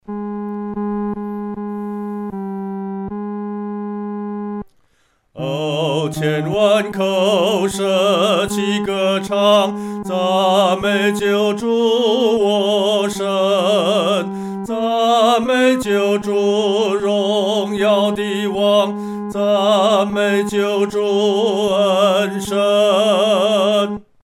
独唱（第三声）
万口欢唱-独唱（第三声）.mp3